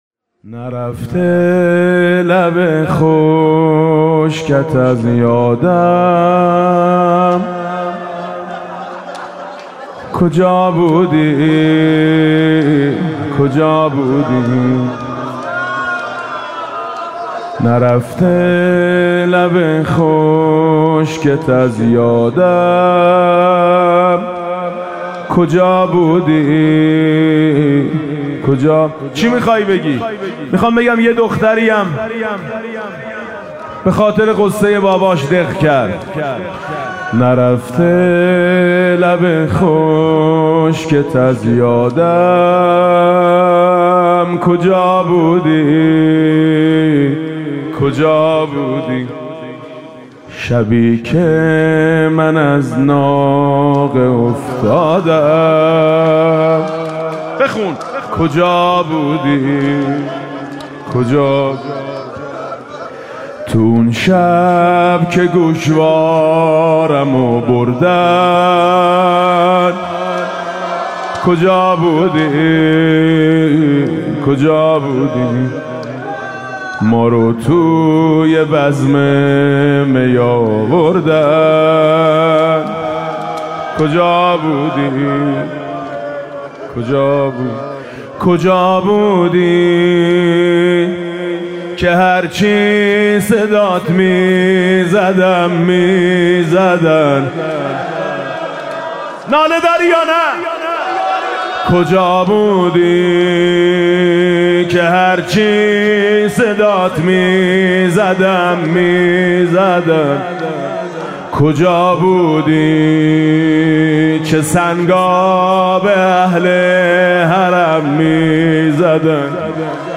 مناسبت: قرائت دعای ۱۴ صحیفه سجادیه و عزاداری ایام شهادت حضرت زهرا (س)
با نوای: حاج میثم مطیعی
چرا از سه ساله‌ا‌ت تو دور بودی؟ (زمزمه)